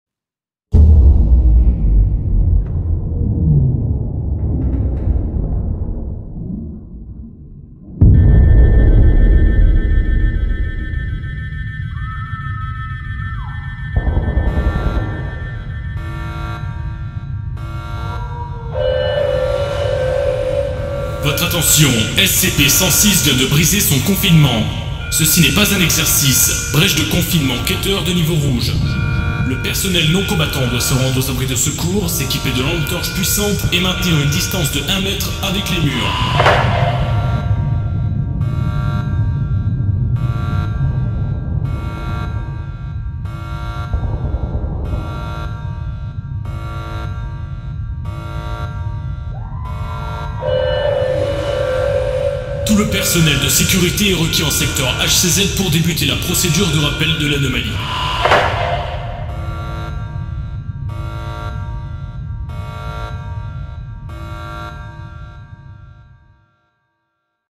Alarme-SCP-VF-Brèche-de-SCP-106.wav